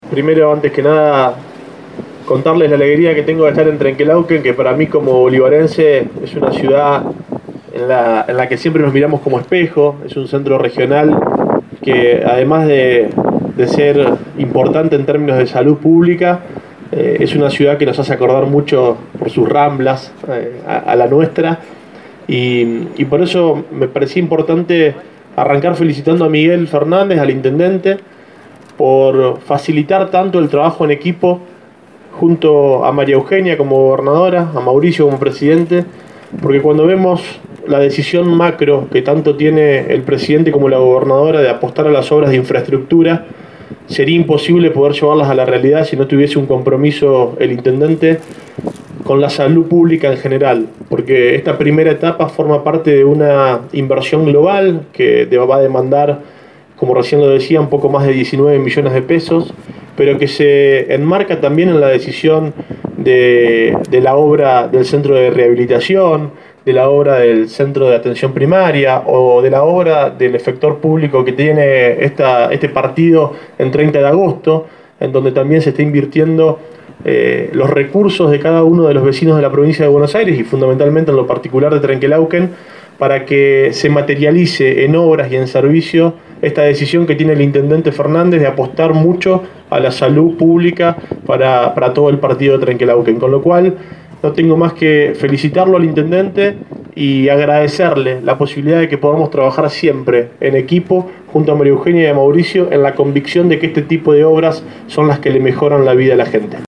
Juan Manuel Mosca presidente de la Cámara de Diputados de la Provincia de Buenos Aires estuvo en la inauguración del centro oncológico.